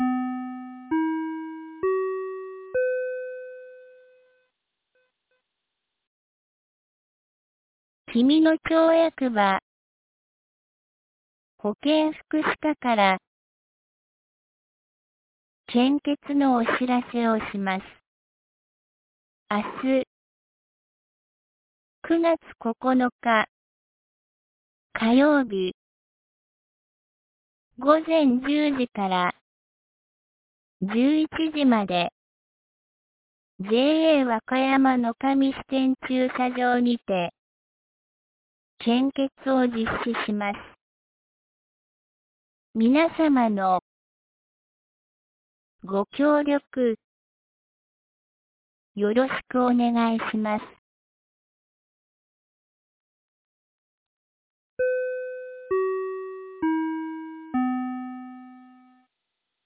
2025年09月08日 17時06分に、紀美野町より全地区へ放送がありました。